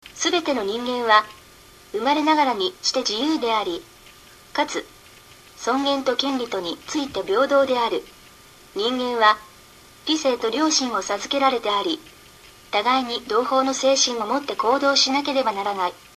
Text to speech
The quality of the speech on these sites varies – for some languages it sounds fairly natural, for others it sounds artificial.
The Japanese and Korean sound completely natural to me- In fact, I could imagine the Japanese voice being, say, a typical radio newscast on NHK.